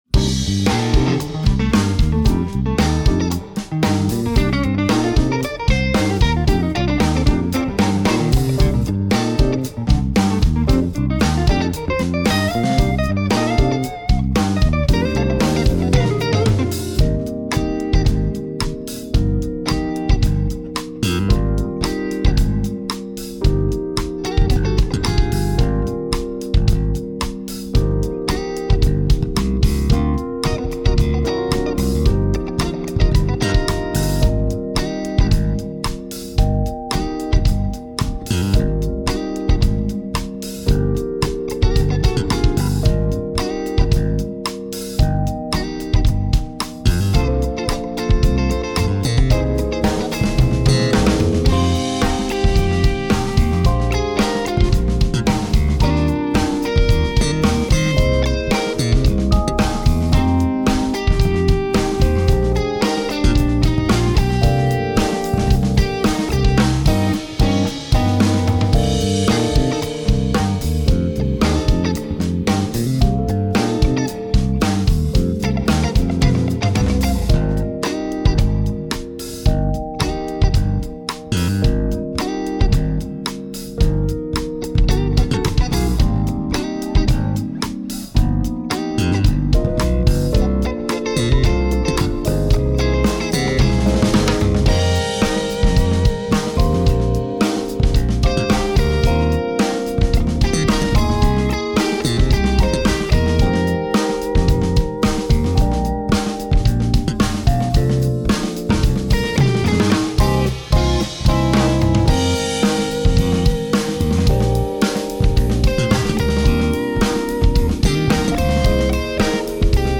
Voicing: Eb Saxophone Collection